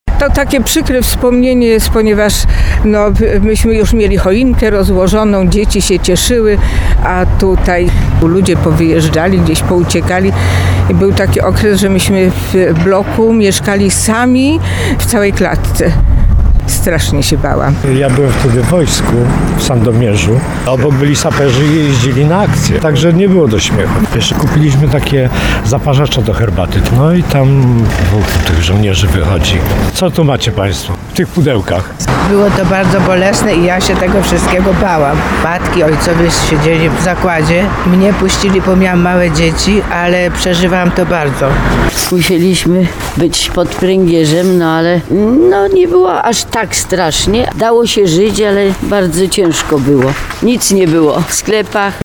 – To jest przykre wspomnienie, ponieważ my już mieliśmy choinkę rozłożoną, dzieci się cieszyły, a tutaj nagle ludzie pouciekali i był taki okres, że w całej klatce w bloku mieszkaliśmy sami. Strasznie się bałam – mówi jedna z tarnowianek, spotkanych na ul. Krakowskiej.